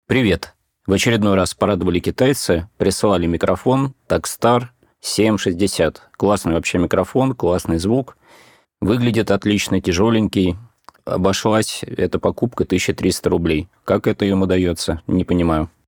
Попытался обработать выложенный вами тест.